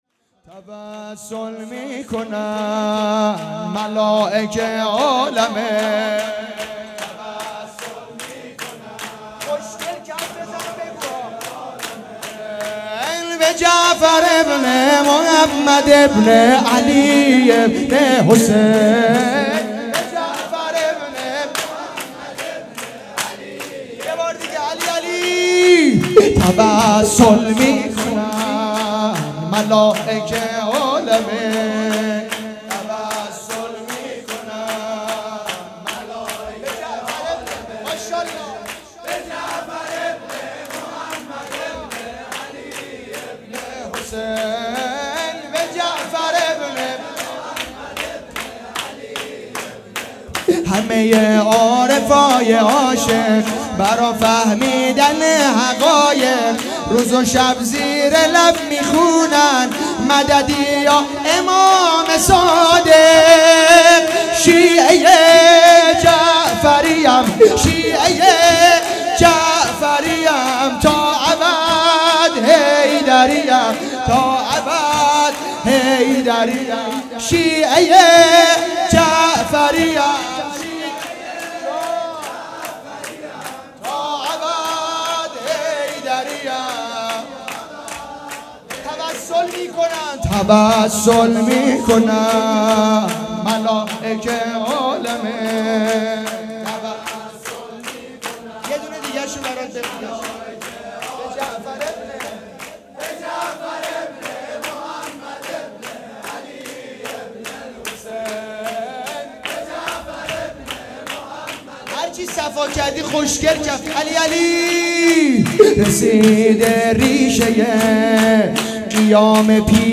توسل میکنن ملایکه عالمین _ شور
جشن ولادت پیامبر اکرم و امام صادق علیه السلام